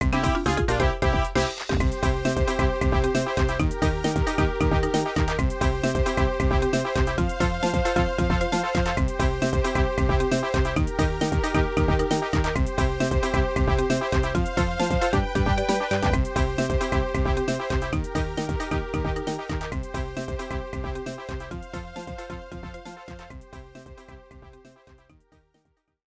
The battle end theme
Ripped from game data, then trimmed in Audacity